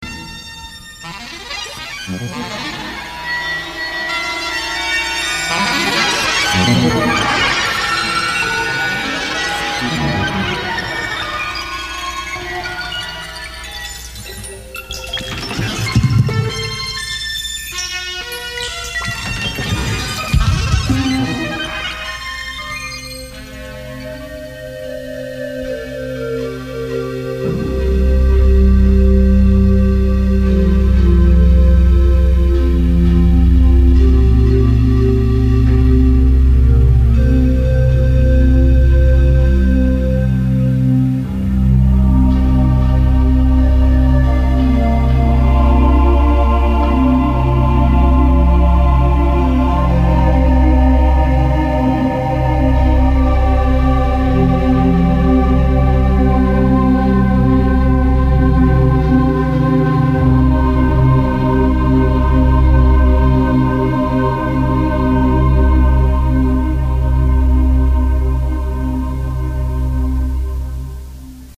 Korg M1 keyboard